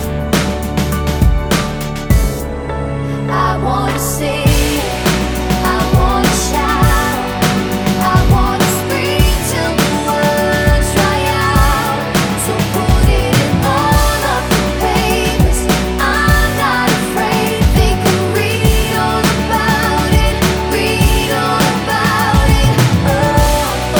Duet Version R'n'B / Hip Hop 3:54 Buy £1.50